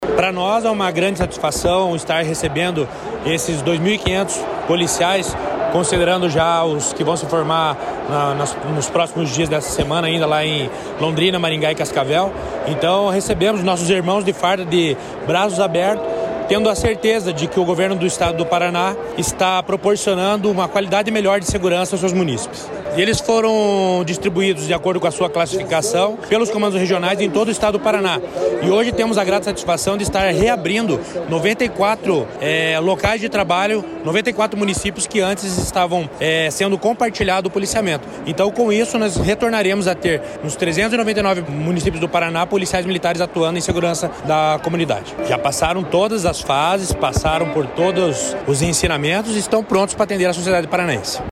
Sonora do comandante-geral da Polícia Militar, coronel Jefferson Silva, sobre a formatura de 1.452 policiais militares para a macrorregião de Curitiba